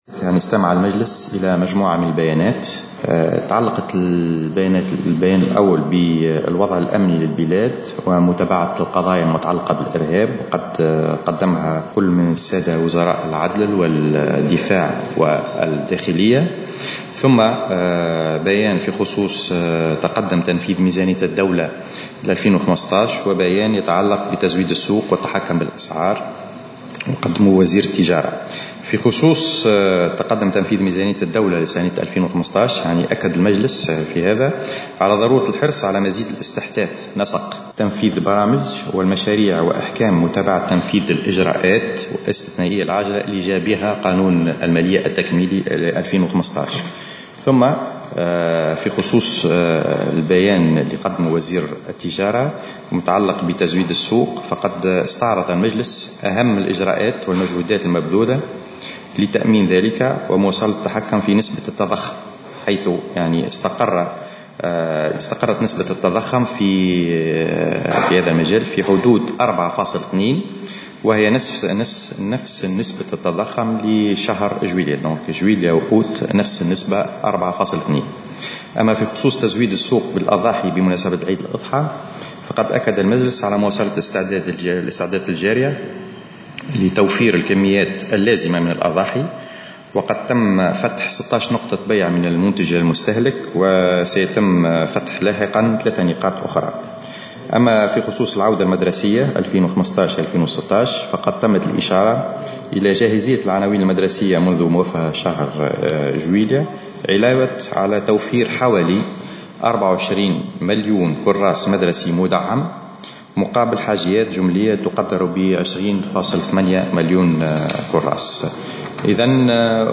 وأفاد على هامش ندوة صحفية عقدها بمقر الحكومة بالقصبة على اثر انعقاد مجلس للوزراء، أنه تم الاستعداد بشكل كامل لتوفير الكميات اللازمة للأضاحي بمناسبة عيد الأضحى الموافق ليوم 24 سبتمبر الحالي، مضيفا أنه تم فتح 16 نقطة بيع وسيتم فتح 3 نقاط أخرى لاحقا.